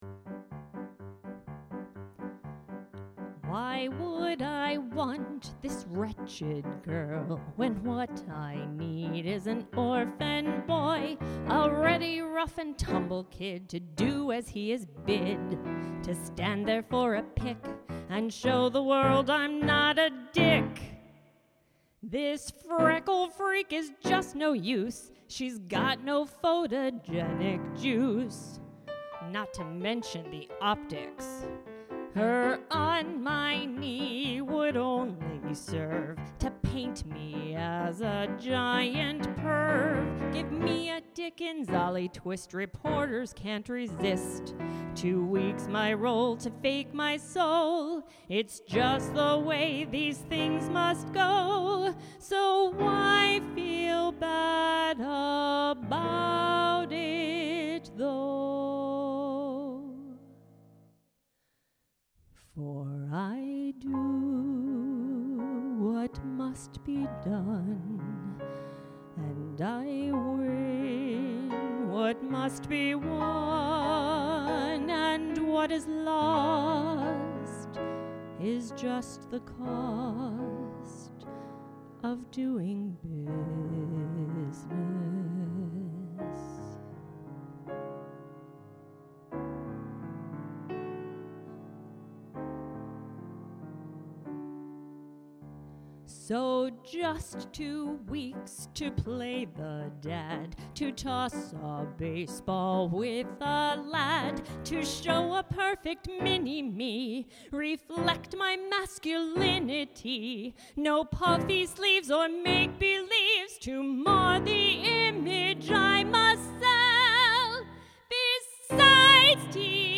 Song Demos
(Music; Demo Song Production and Accompaniment)